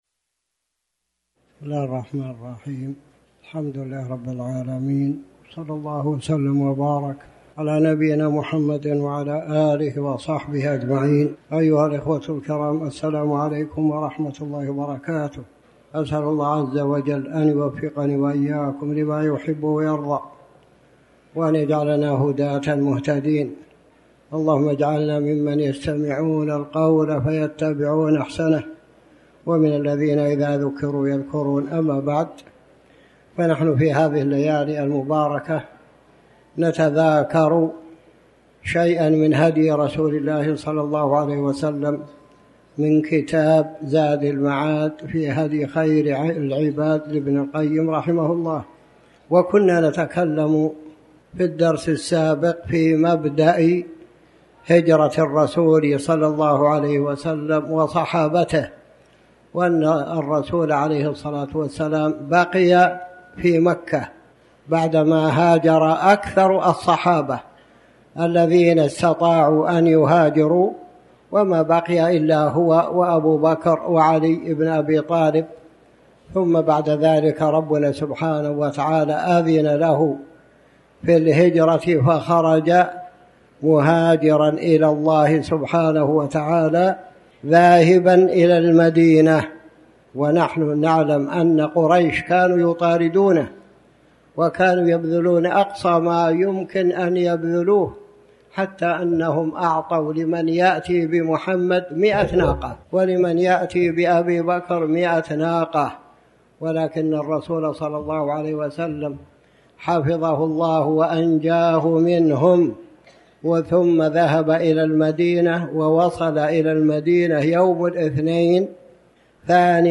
تاريخ النشر ٢٧ ذو الحجة ١٤٤٠ هـ المكان: المسجد الحرام الشيخ